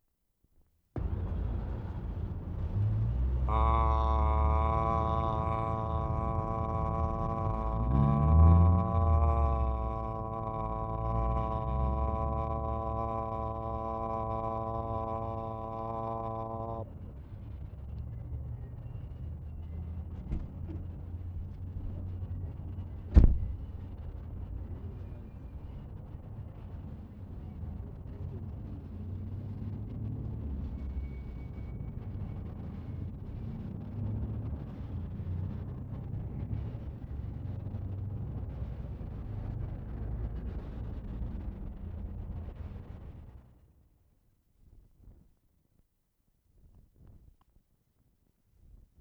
CORNER OF HASTINGS & COLUMBIA ST. Sept. 13, 1972
Note: This entire reel was recorded at 3 3/4 ips, 1/2 tr. st.